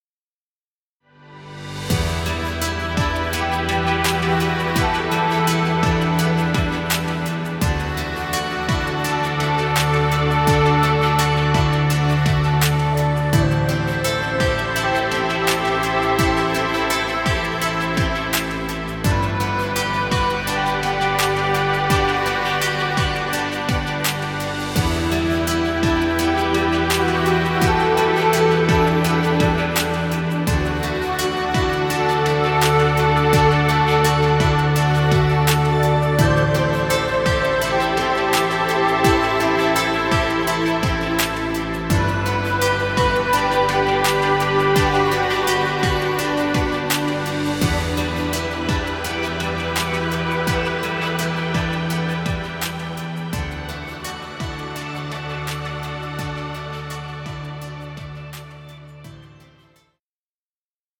Chillout music.